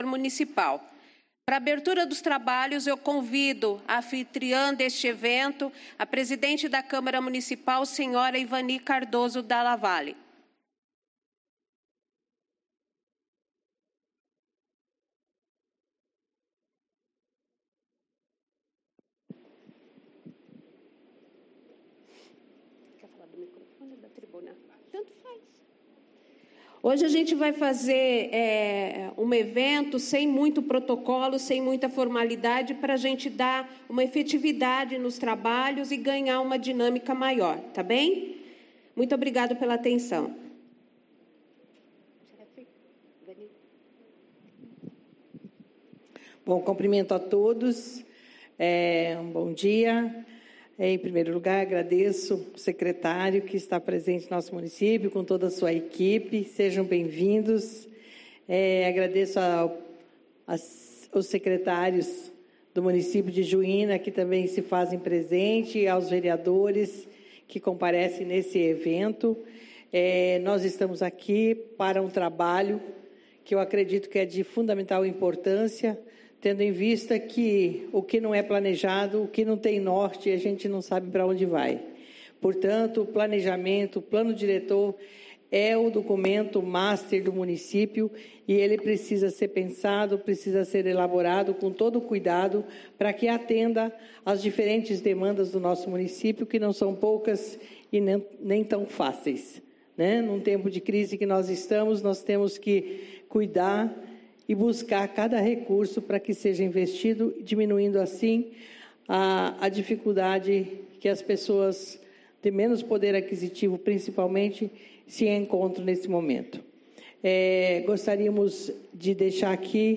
Áudio na íntegra da Audiência Pública para discussão do Plano Diretor Municipal, com a Secretária Estadual de Cidades.